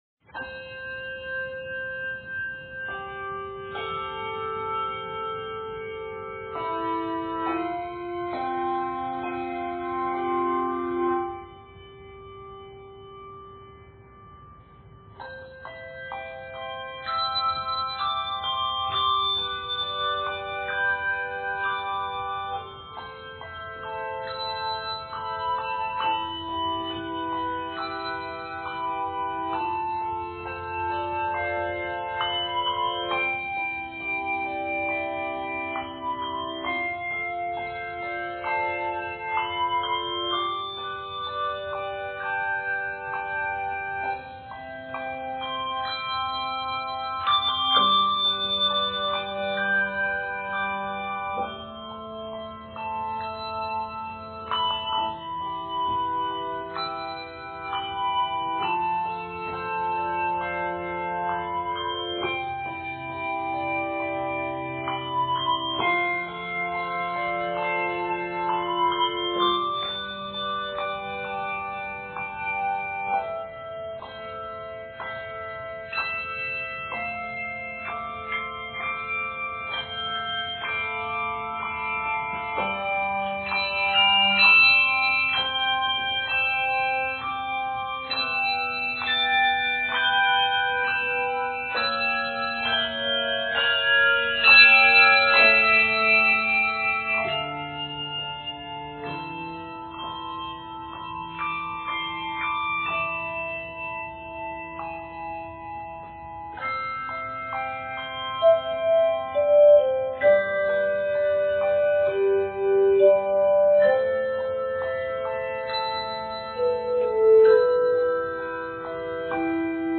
This quiet, reflective work